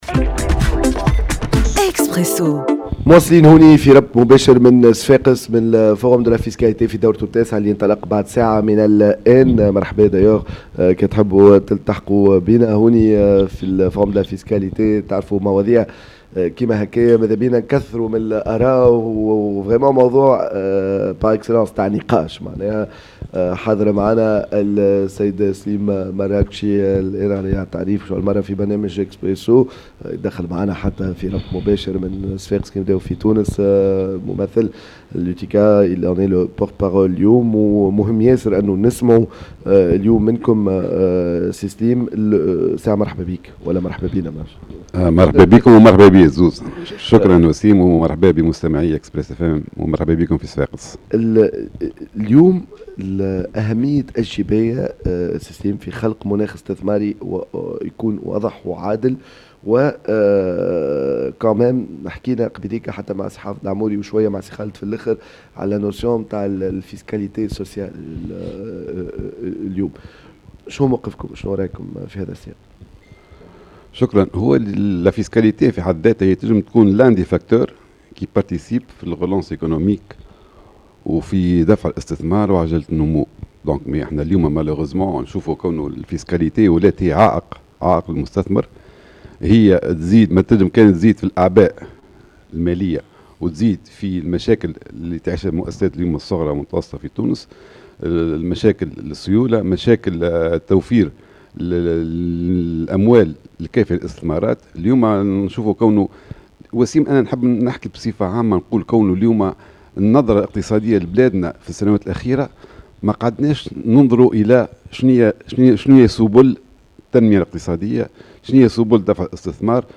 في برنامج اكسبرسو مباشرة من منتدى الجباية في نسخته التاسعة